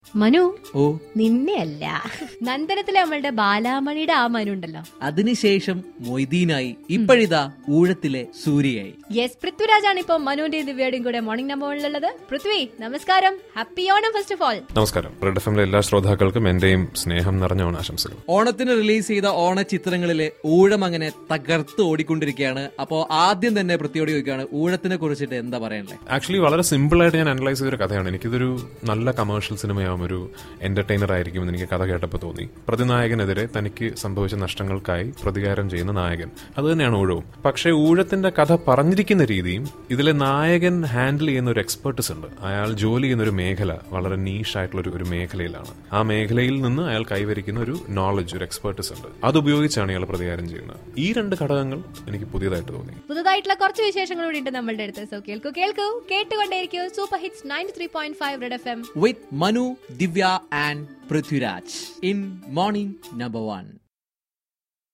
INTERVIEW WITH PRITHVIRAJ.